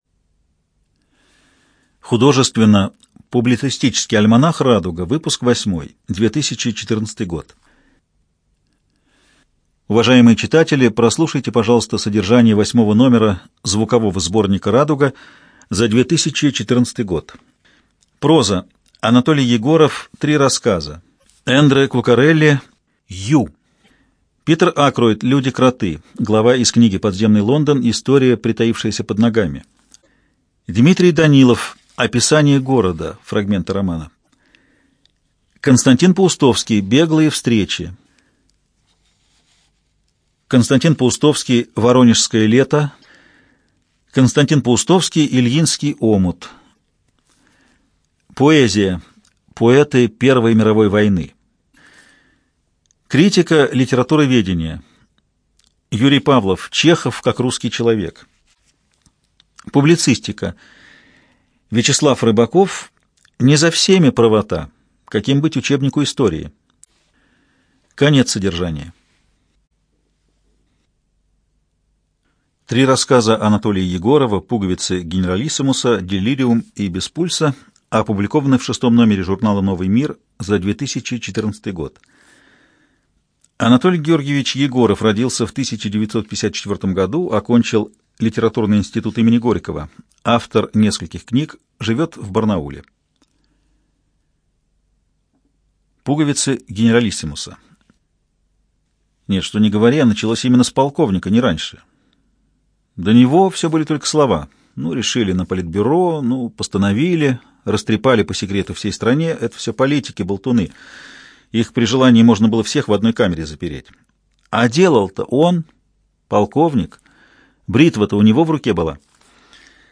ЖанрПублицистика
Студия звукозаписиЛогосвос